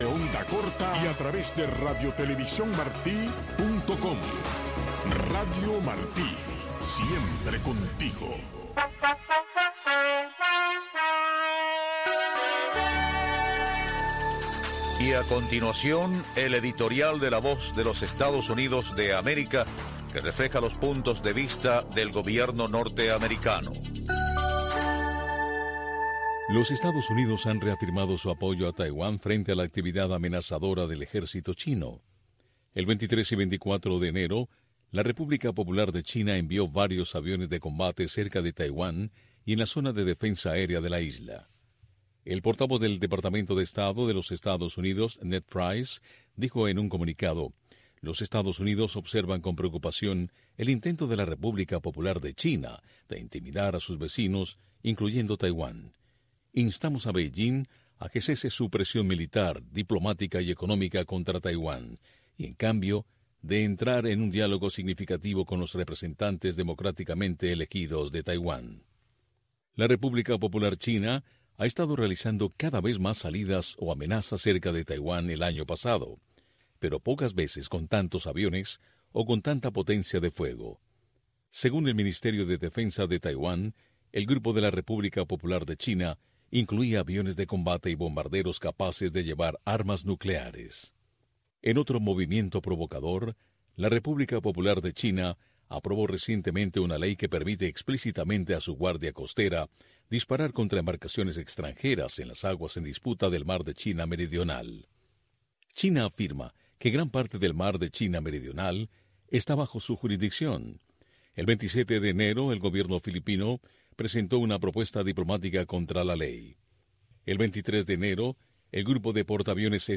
revista de entrevistas